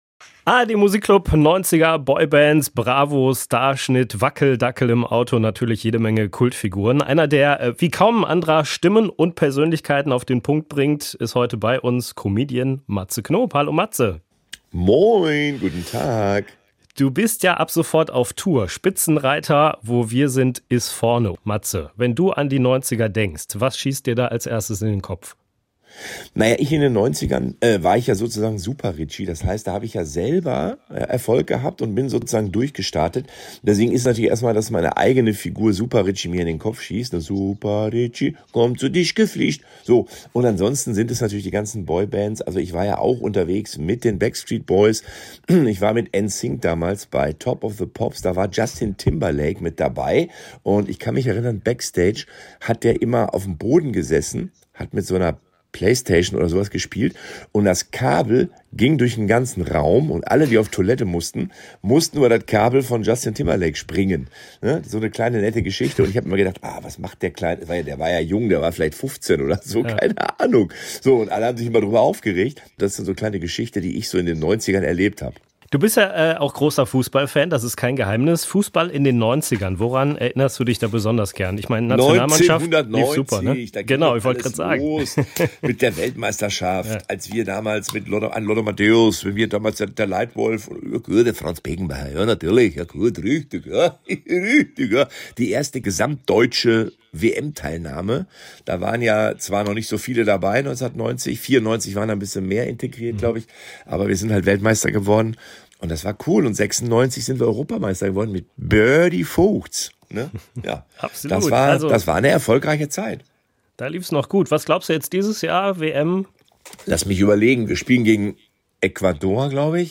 Mehr Interviews bei SWR1 RLP